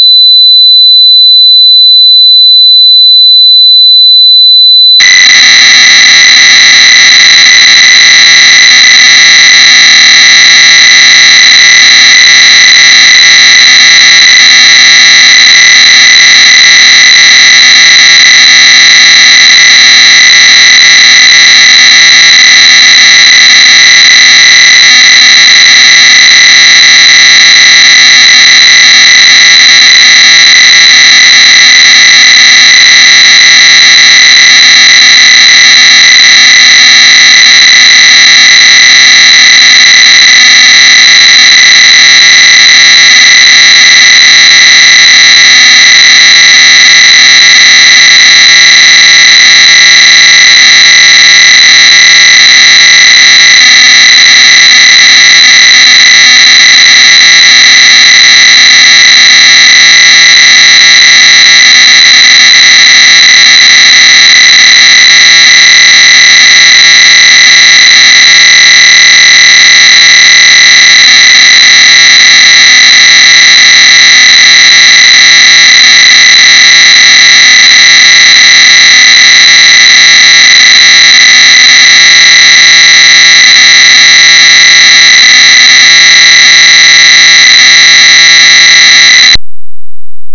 The source code can be converted to a WAV file using the Pocket Tools to transfer the program to the Sharp Pocket PC.
The *.wav file must be loaded on the PC-1350 / PC-1360 with CLOAD.